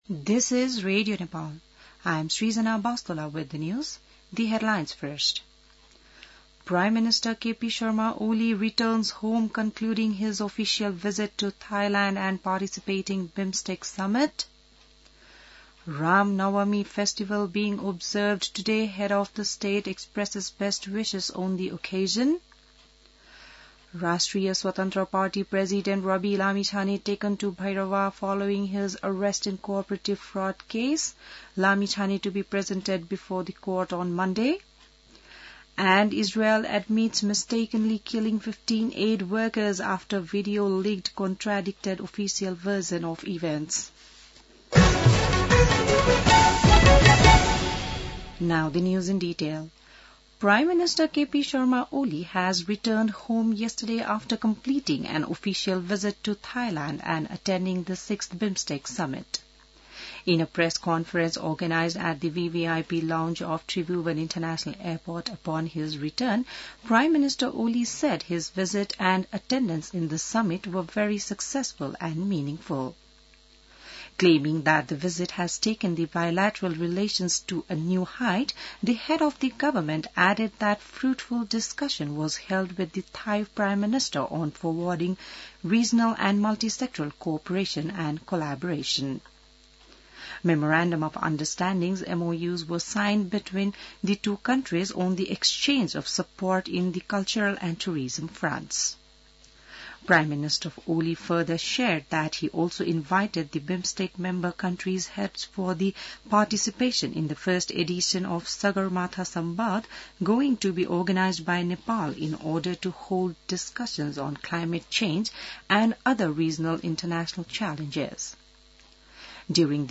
बिहान ८ बजेको अङ्ग्रेजी समाचार : २४ चैत , २०८१